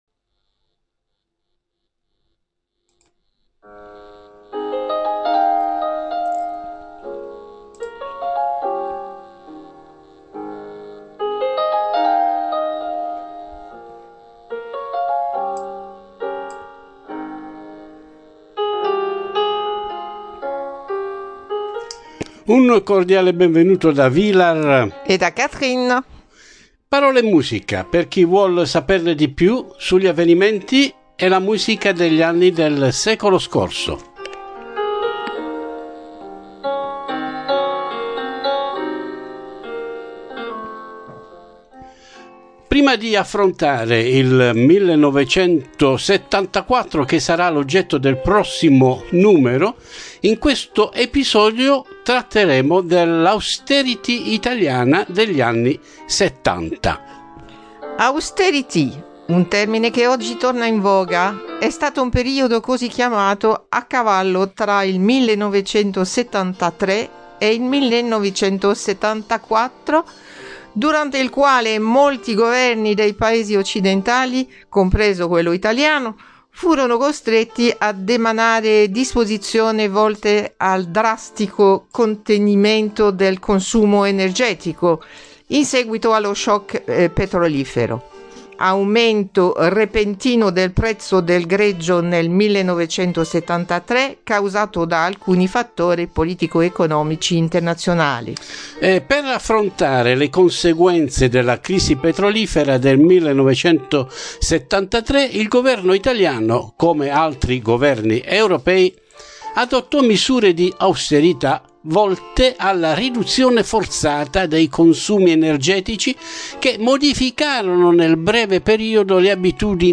Parole & Musica : un ricordo degli anni passati partendo dal dopoguerra fino ai giorni nostri; una “carrellata” degli avvenimenti del secolo scorso, con la rispettiva musica di quegli anni